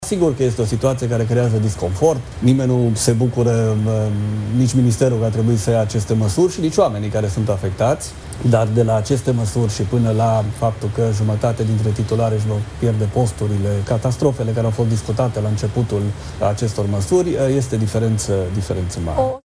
Ministrul Daniel David, într-un interviu la Prima News: „Încercăm să ușurăm activitatea celorlalți, chiar dacă vorbim de 1,4%, fiecare om contează”